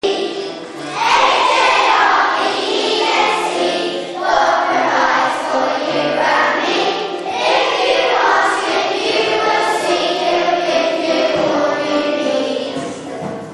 Loders Church was decorated with flowers for this year's harvest festival service.
Listen to us singing